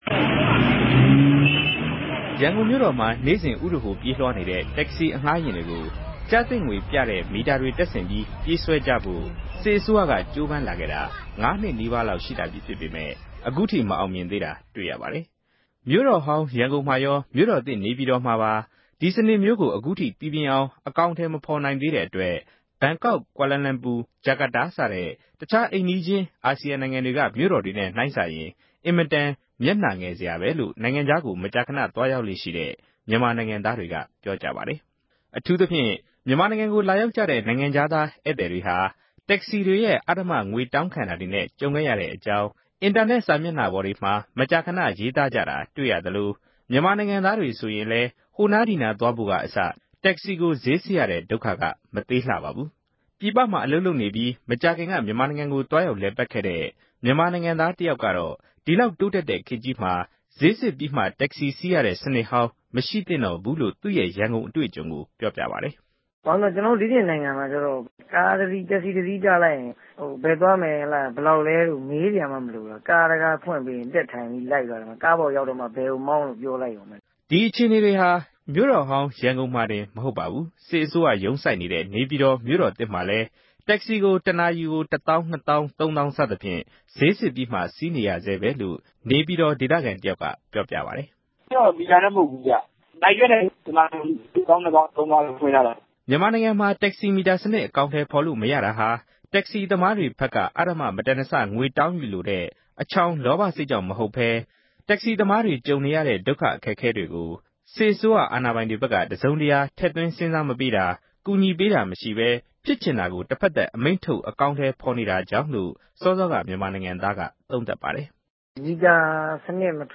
လေ့လာတင်ပြချက်